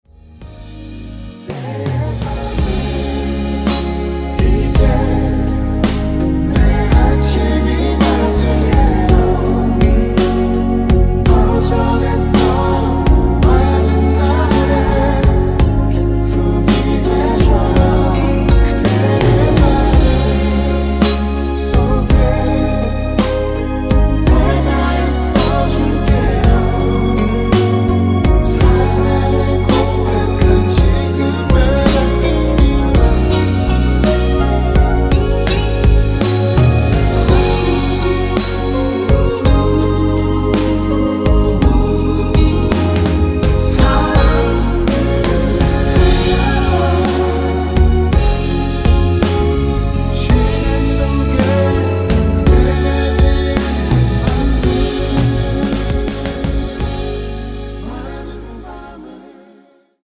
원키에서(-1)내린 (2절 삭제) 코러스 포함된 MR입니다.(미리듣기 참조)
Db
앞부분30초, 뒷부분30초씩 편집해서 올려 드리고 있습니다.
중간에 음이 끈어지고 다시 나오는 이유는